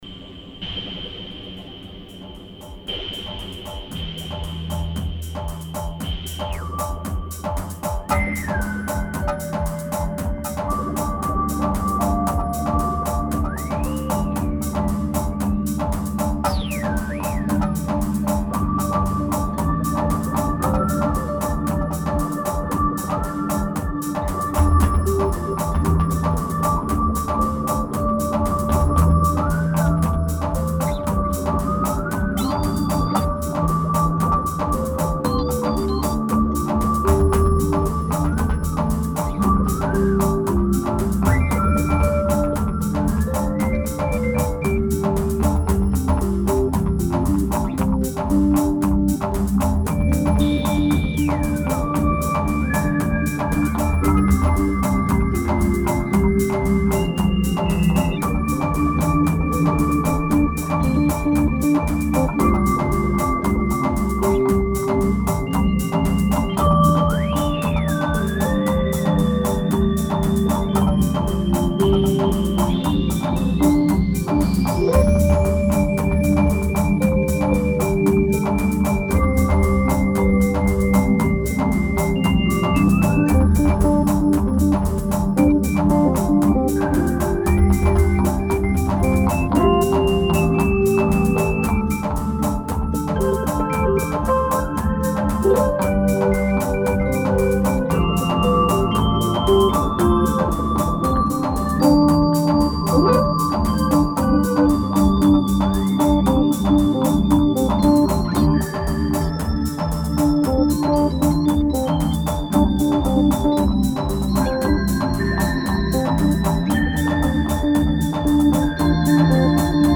Instrumental. Romantisches Strand Ambiente.
Tempo: 60 bpm / Datum: 21.12.2015